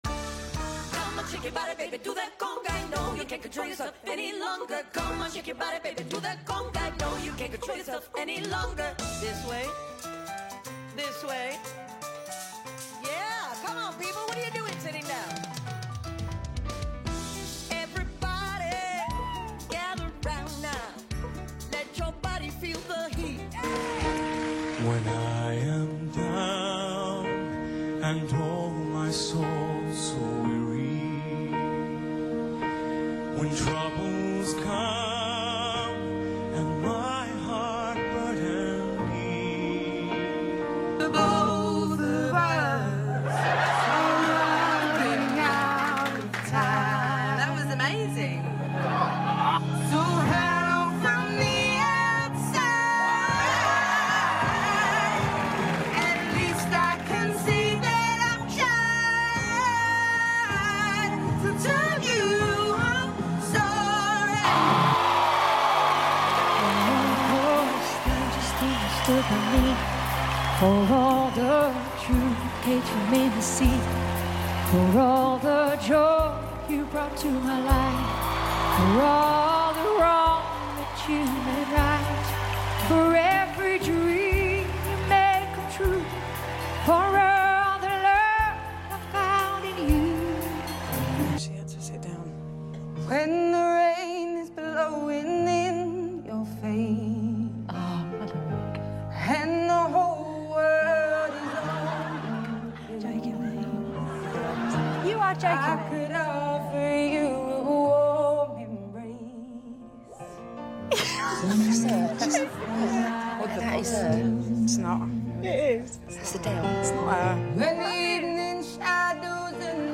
Top 5 Ranked Surprise Singing Moments on TV Shows